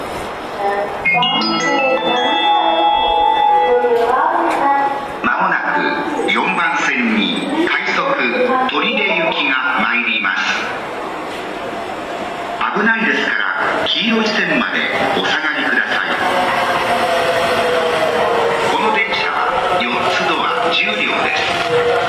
ただ、京成線・新幹線・宇都宮線・高崎線の走行音で発車メロディの収録は困難です。
接近放送